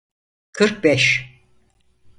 Pronounced as (IPA)
[kɯɾk bɛʃ]